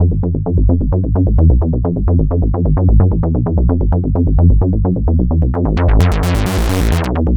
Index of /90_sSampleCDs/Club_Techno/Bass Loops
BASS_130_F.wav